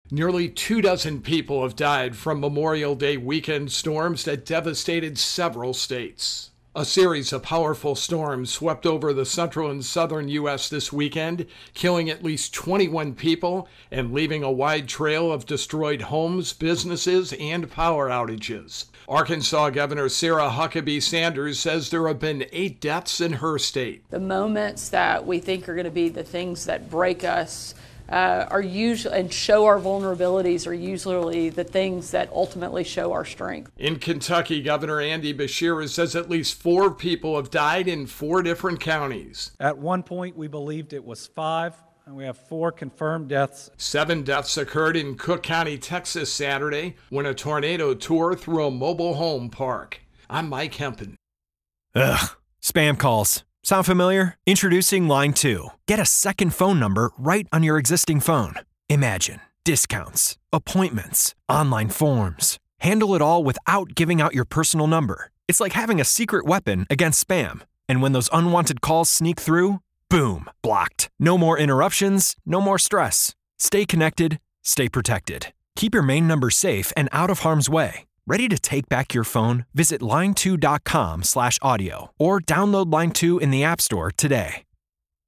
Nearly two dozen people have died from Memorial Day weekend storms that devastated several states. AP correspondent